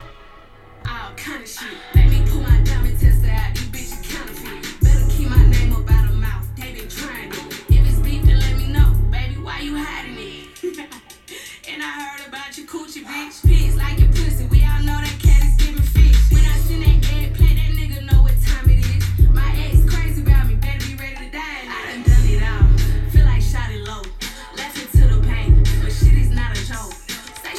Well since I've not uploaded any # ElevenLabs content in a while, here's the stock Adam voice giving a speech, even though he's crap at writing them. but apparently the reason why people still hire him is because of the demonic noises he finishes with.
And he doesn't seem to know what his accent is supposed to be.